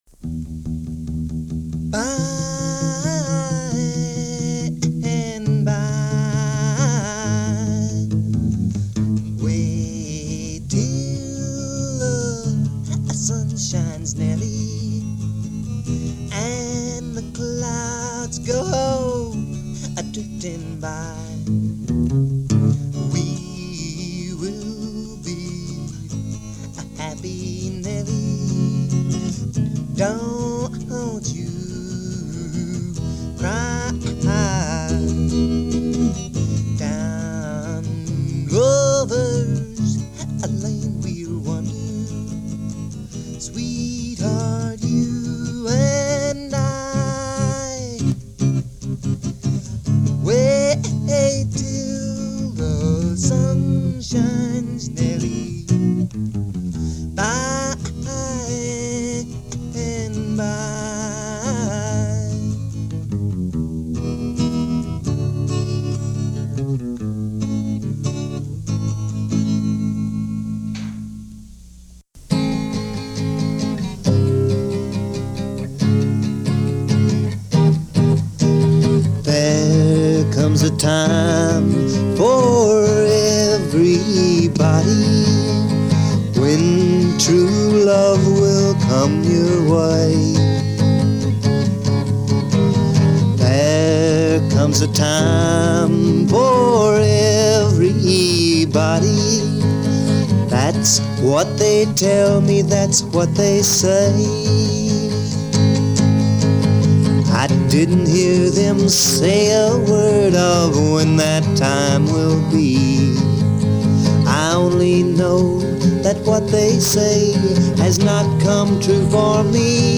vocal and acoustic guitar
Home Recording Undubbed